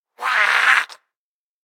DayZ-Epoch/SQF/dayz_sfx/zombie/spotted_9.ogg at e917eb72afc8fbac249169a18c2ec1c74ed634a0